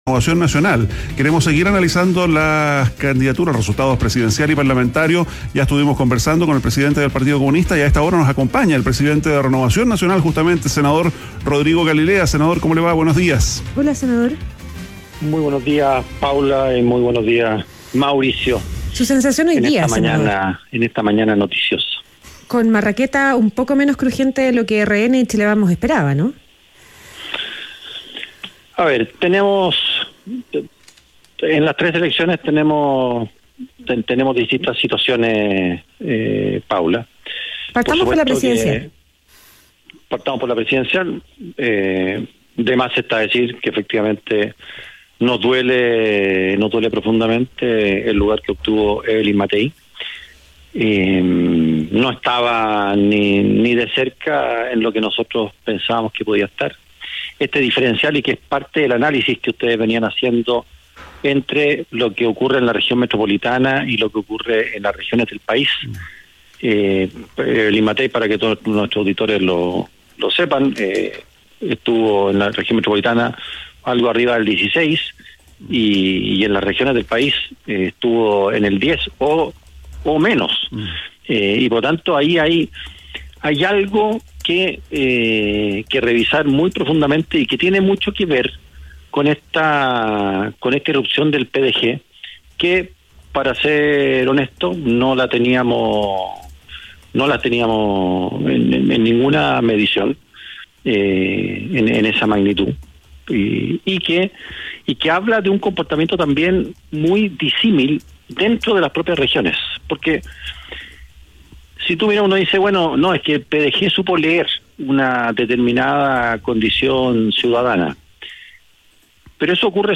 ADN Hoy - Entrevista a Rodrigo Galilea, senador y presidente de Renovación Nacional